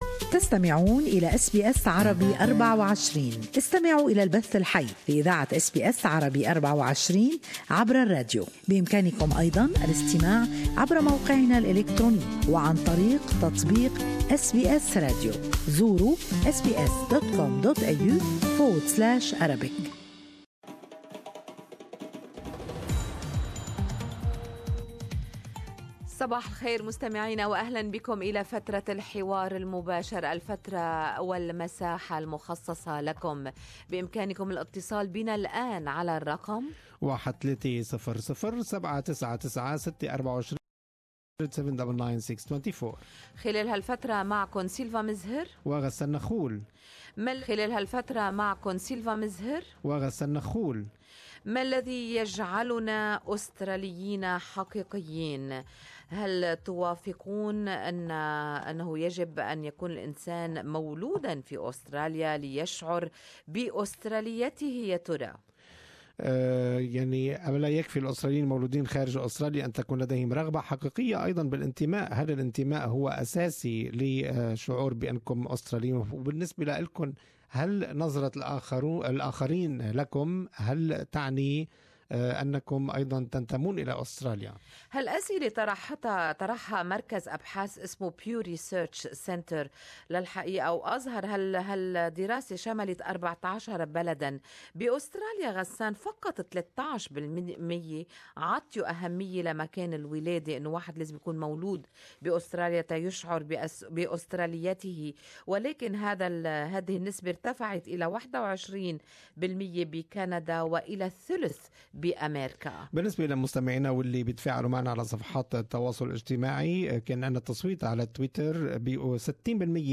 Different things make us Aussies, what makes you an Aussie?Is it the Language, openness to other culture, our love to Vegimite and BBQ's or what?Good Morning Australia listeners shared their opinions on the topic.